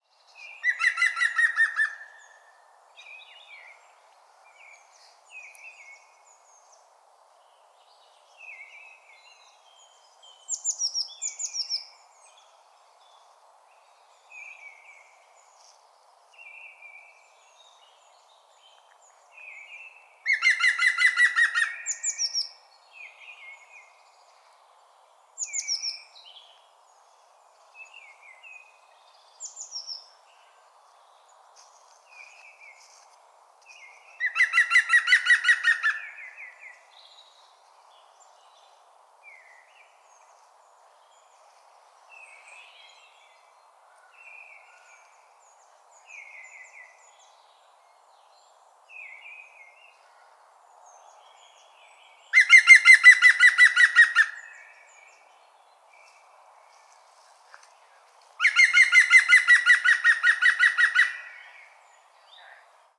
Während das Männchen mit seinem „lachenden“ Rufen oft für Aufmerksamkeit sorgt, bleibt das Grünspecht Weibchen meist im Hintergrund.
Grünspecht Ruf
Der-Gruenspecht-Ruf-Voegel-in-Europa.wav